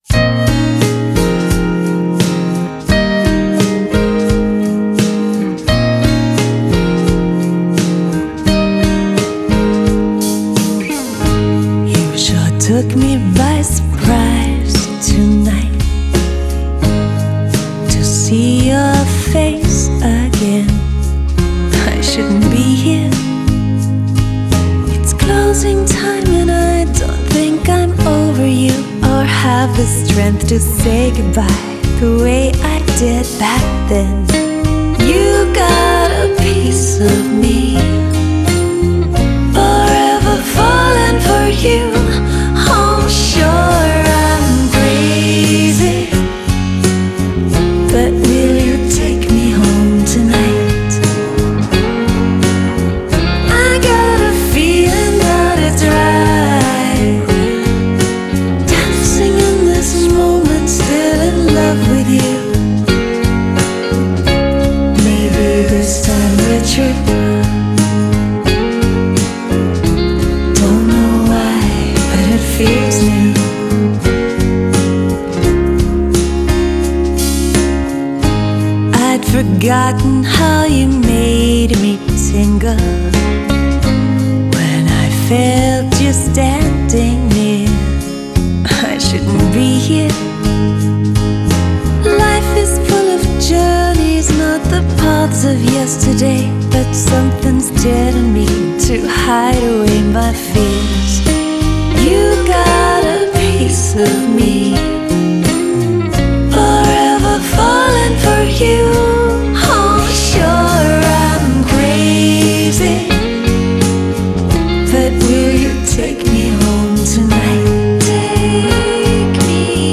piano and backing vocals
guitar
drums
sax